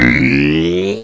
cartoon